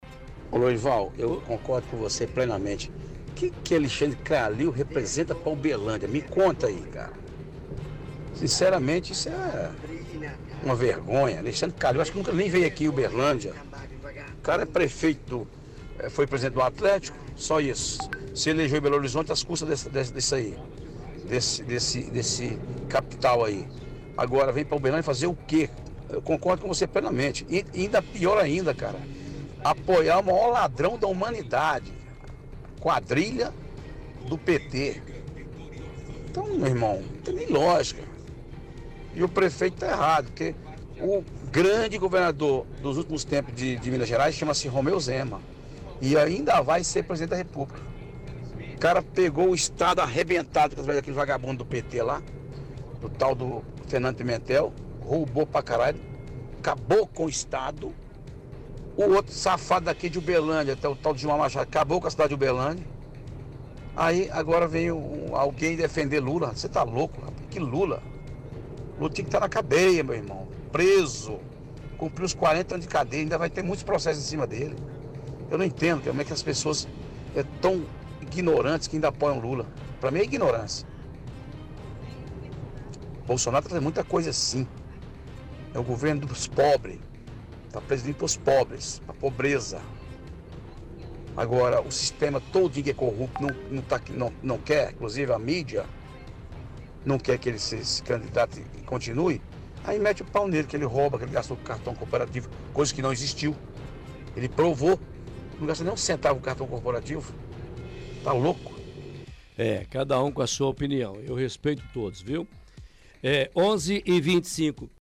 – Ouvinte se posiciona contra o título de cidadão a Kalil.